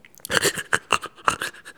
reniflement-animal_02.wav